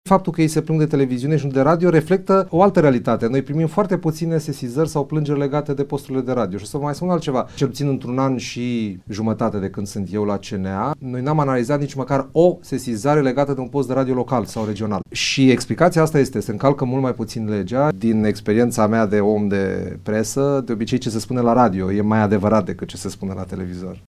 Aceasta este opinia lui Radu Herjeu, membru în Consiliul Naţional al Audiovizualului, invitat azi în emisiunea „Părerea ta” de la Radio Tîrgu-Mureş.
stiri-12-iul-herjeu-radio.mp3